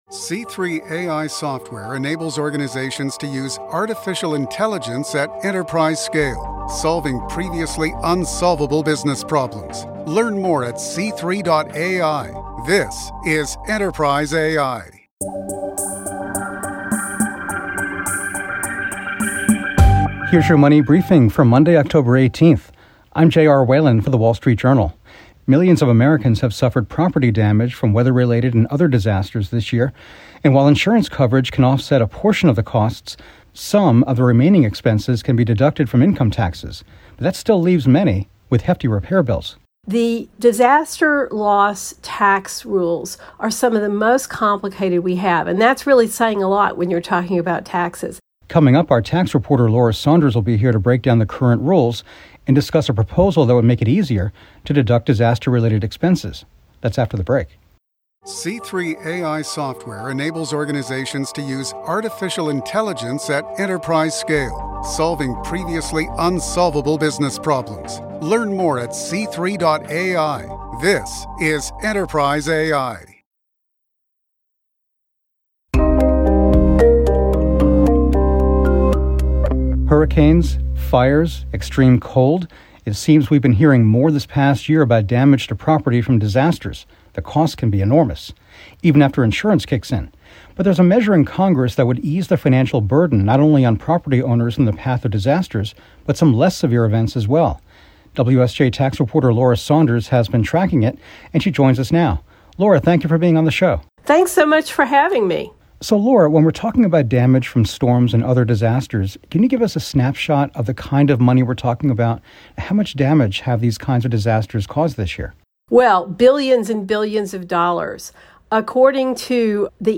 For millions of Americans hit by weather-related disasters, insurance only covers so much of the expense. WSJ tax reporter